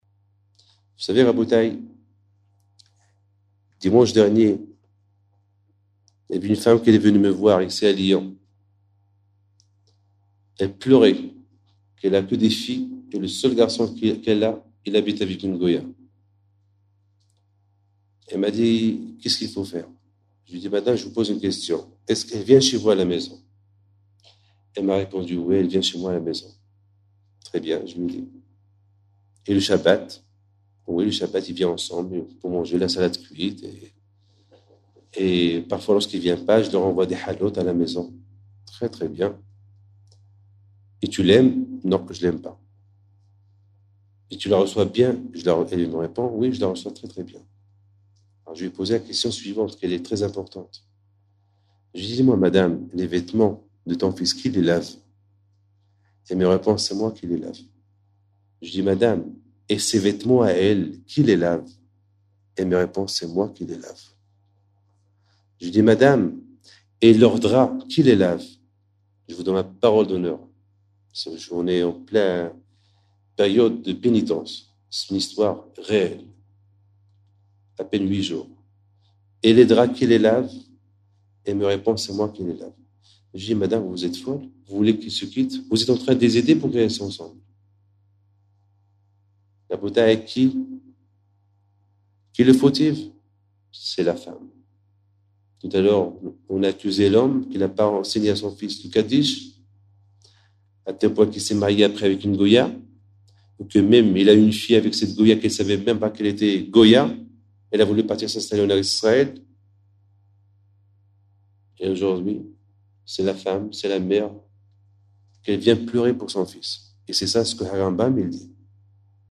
01:20:03 Nous sommes entre le 17 Tamouz et le 9 Av 5748, le 12 juillet 1988 à Villeurbanne devant une salle comble.